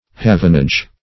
Havenage \Ha"ven*age\ (-[asl]j), n. Harbor dues; port dues.